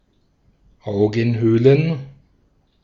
Ääntäminen
Ääntäminen Tuntematon aksentti: IPA: /ˈʔaʊ̯ɡŋ̩ˌhøːln̩/ IPA: /ˈʔaʊ̯ɡənˌhøːlən/ Haettu sana löytyi näillä lähdekielillä: saksa Käännöksiä ei löytynyt valitulle kohdekielelle. Augenhöhlen on sanan Augenhöhle monikko.